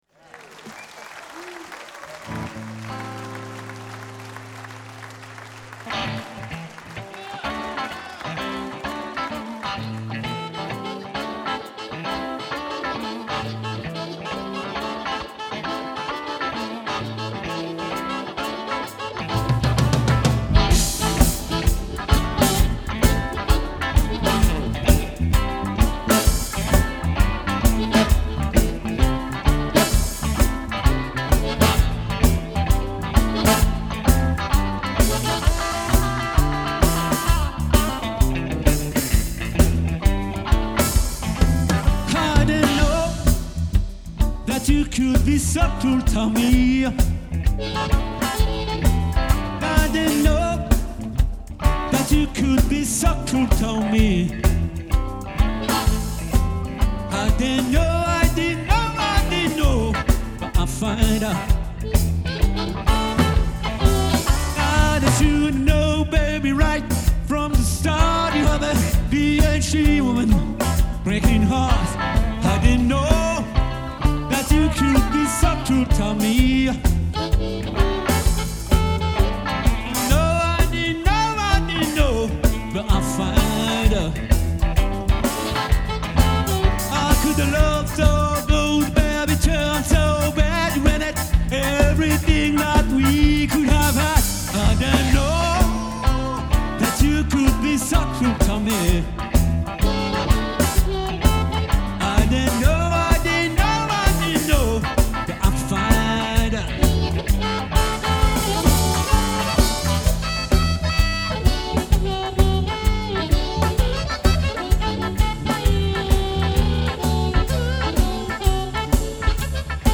guitare
chant basse harmonica
batterie
saxophone